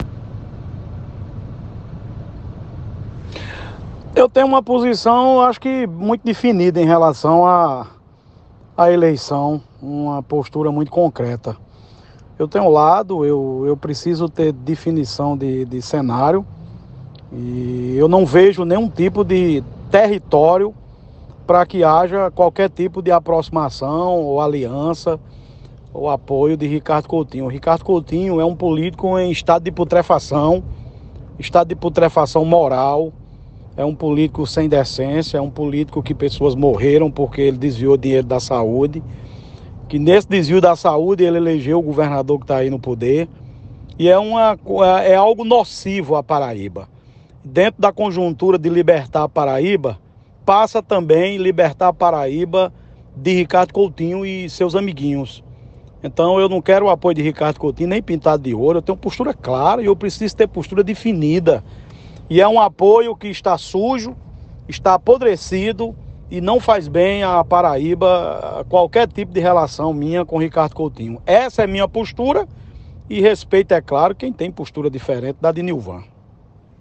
Em entrevista na Hora H, programa da Rede Mais Rádio